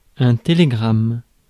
Ääntäminen
IPA : /ˈtɛləˌɡɹæm/